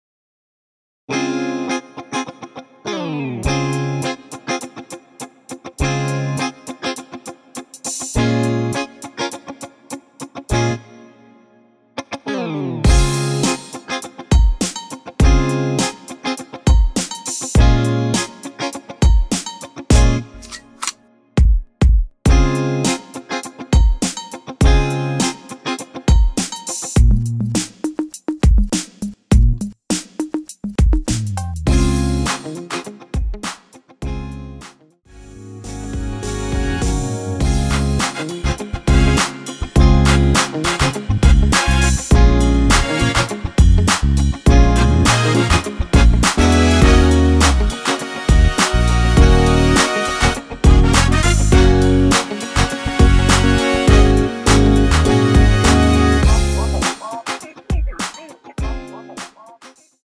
전주 없이 시작 하는 곡이라서 1마디 전주 만들어 놓았습니다.(미리듣기 확인)
3초쯤 노래 시작 됩니다.
Db
앞부분30초, 뒷부분30초씩 편집해서 올려 드리고 있습니다.
중간에 음이 끈어지고 다시 나오는 이유는